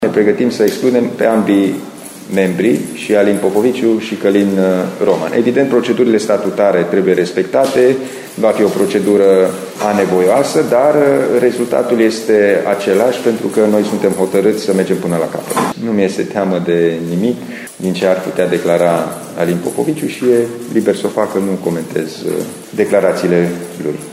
Președintele interimar al PNL Timiș, Alin Nica, a declarat că nu se teme de ceea ce ar putea declara Alin Popoviciu: